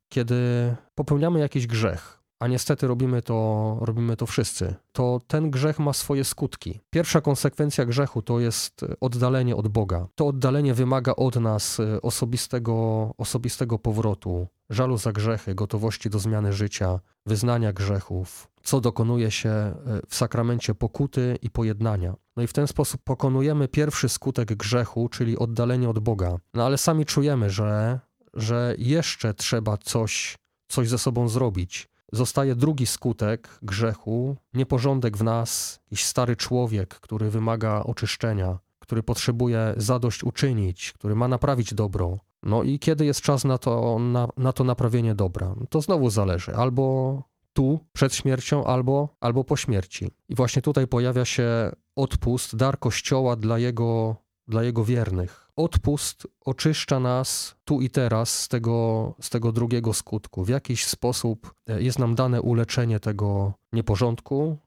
Bp Małyga tłumaczy czym jest odpust.